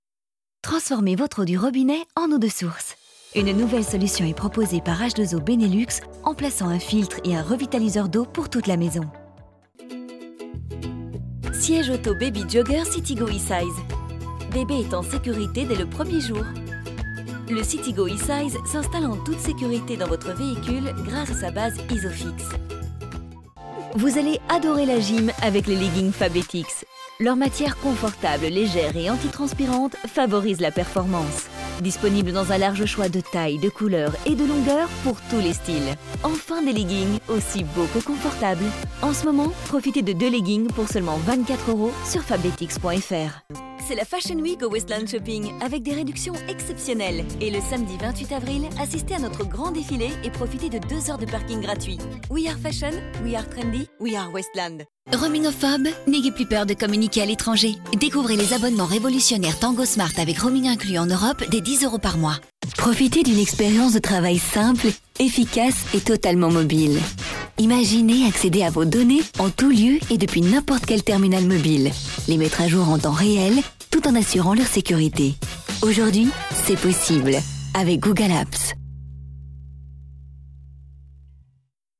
I like to modulate my voice according to the products.
Sprechprobe: Sonstiges (Muttersprache):
Professional actress without accent for more than 10 years!